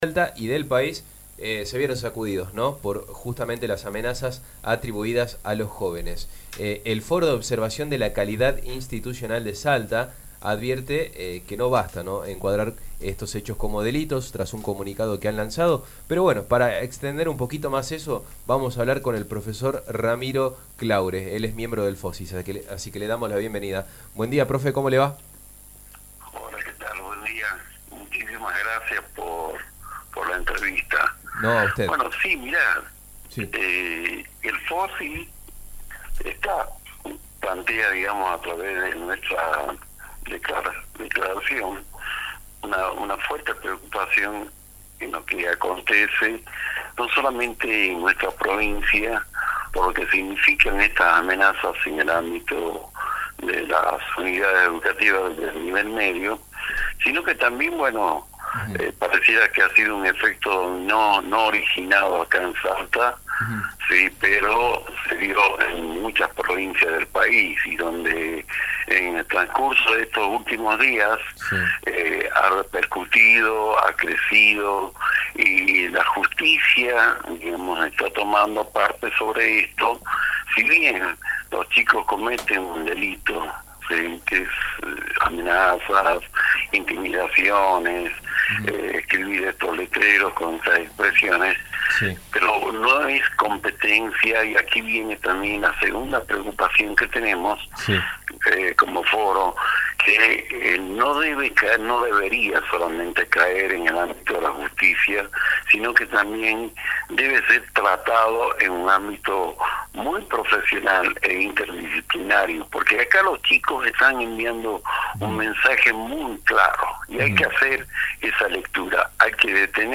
En entrevista con nuestro medio